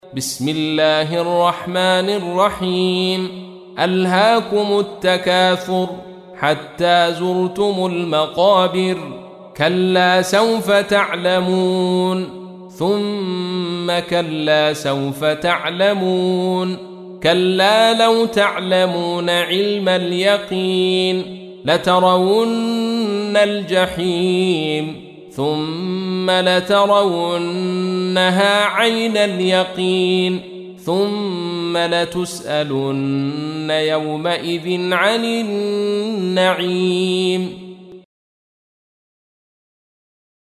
تحميل : 102. سورة التكاثر / القارئ عبد الرشيد صوفي / القرآن الكريم / موقع يا حسين